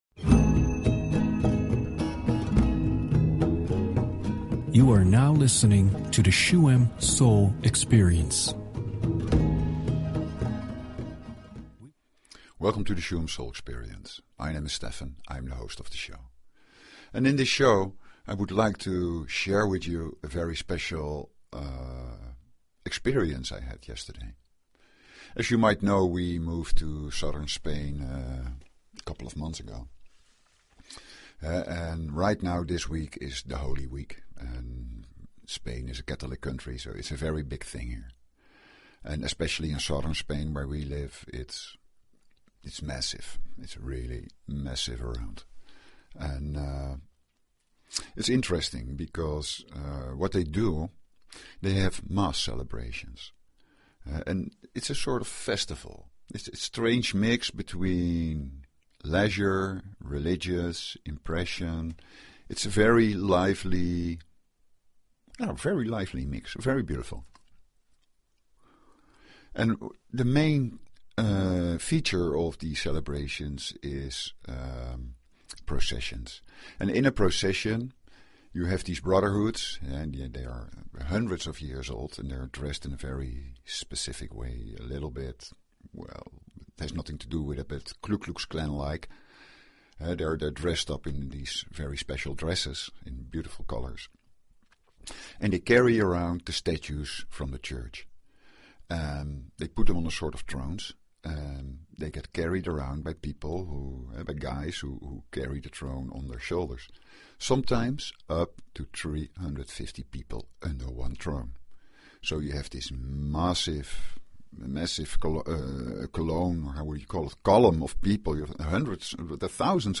Talk Show Episode
Let the sounds and energy flow through and experience a more silent state within yourself.